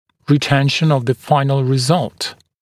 [rɪ’tenʃn əv ðə ‘faɪnl rɪ’zʌlt][ри’тэншн ов зэ ‘файнл ри’залт]сохранение окончательного результата, ретенция с целью сохранения окончательного результата